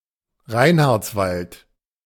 The Reinhardswald (German pronunciation: [ˈʁaɪnhaʁtsˌvalt]